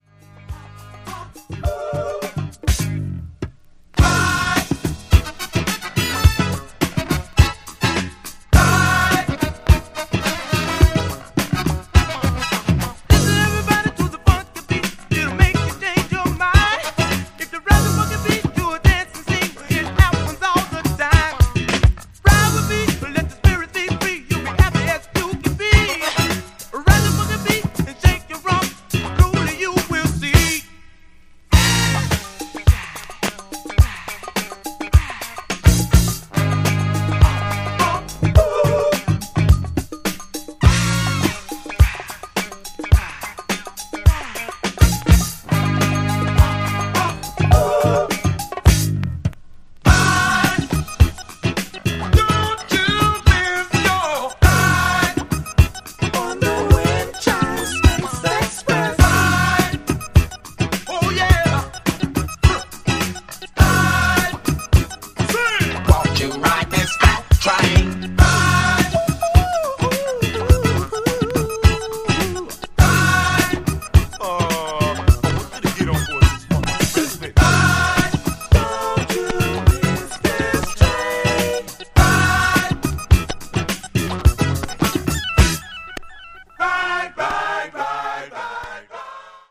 ジャンル(スタイル) FUNK / MODERN SOUL